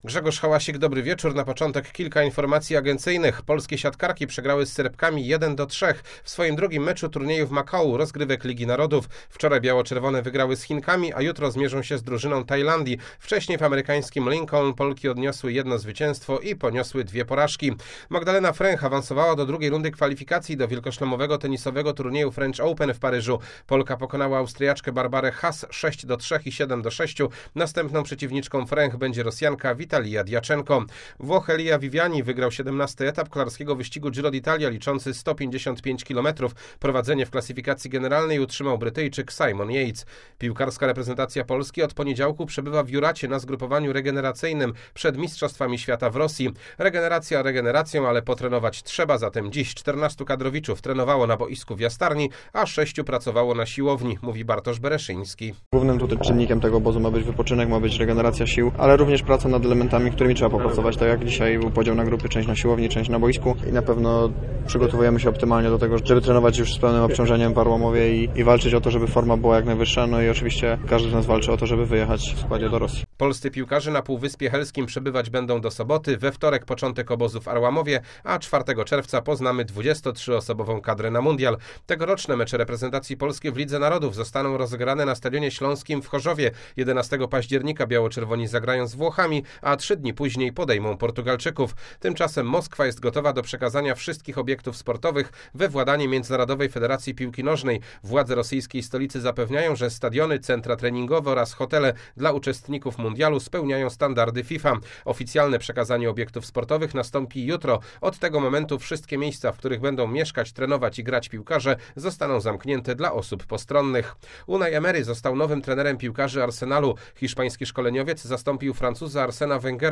23.05 serwis sportowy godz. 19:05